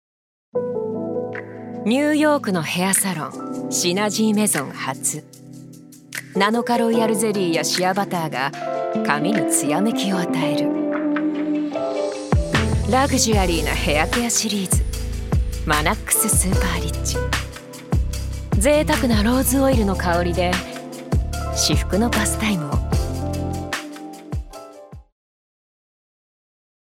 ジュニア：女性
ナレーション２